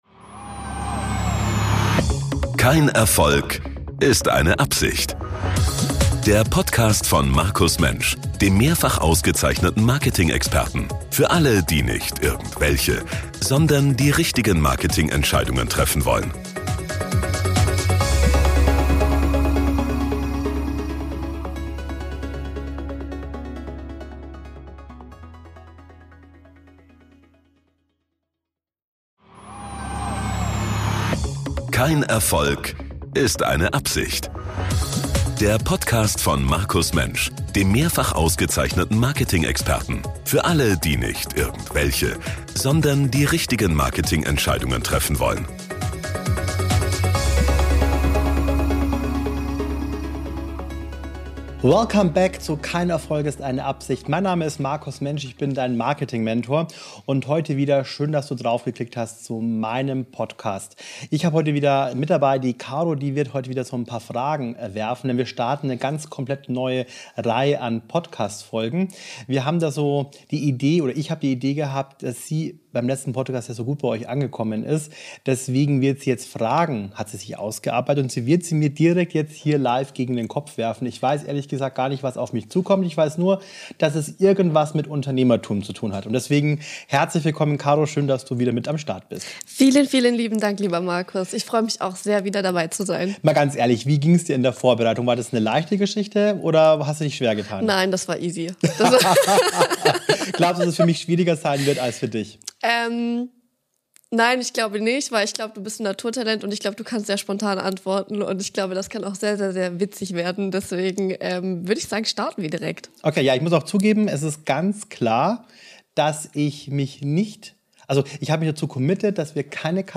Ich antworte unzensiert und völlig spontan!